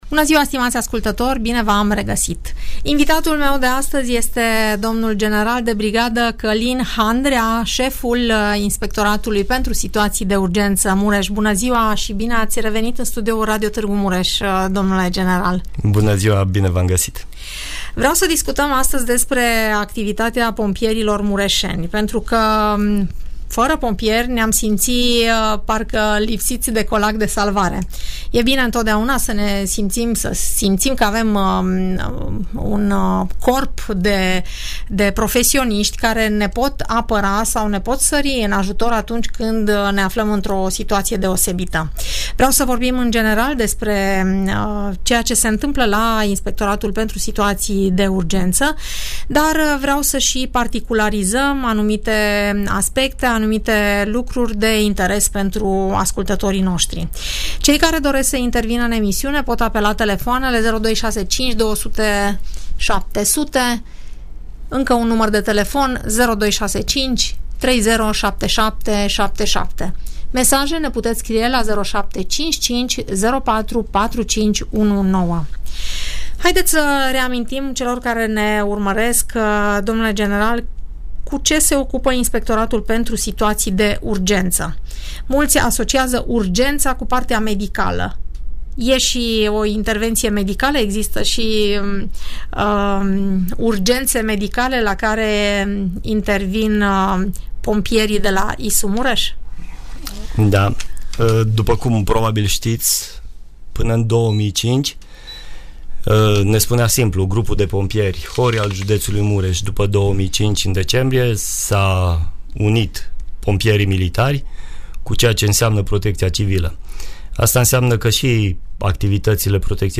Seful Inspectoratului pentru Situații de Urgență Mureș, dl general de brigadă Călin Handrea, vorbește la Radio Tg. Mureș, despre activitatea instituției, evenimentele nedorite la care pompierii sau paramedicii au intervenit și despre ceea ce putem face pentru a le evita.